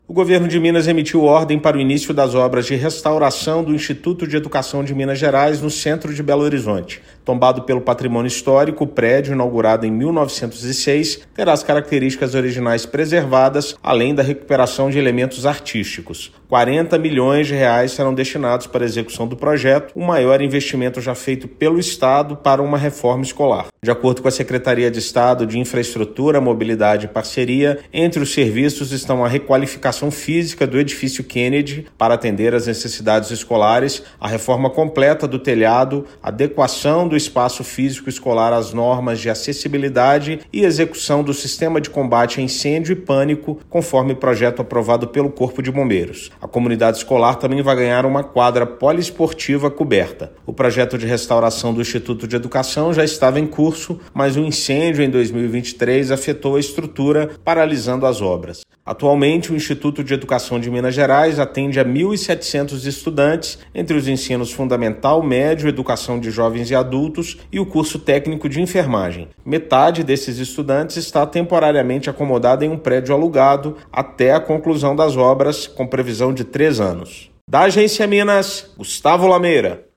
Reforma vai garantir mais acessibilidade e segurança aos estudantes, além de preservar patrimônio histórico. Ouça a matéria de rádio.